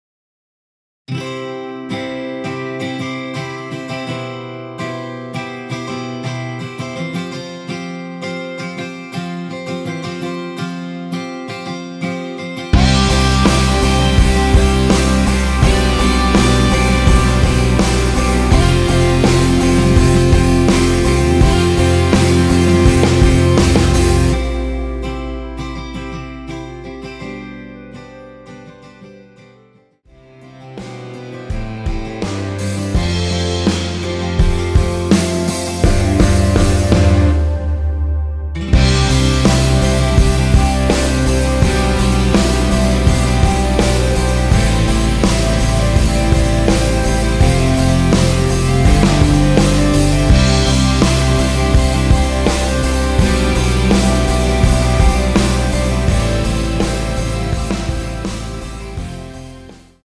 원키(1절앞+후렴)으로 진행되게 편곡된 멜로디 포함된 MR입니다.(미리듣기 확인)
앞부분30초, 뒷부분30초씩 편집해서 올려 드리고 있습니다.
중간에 음이 끈어지고 다시 나오는 이유는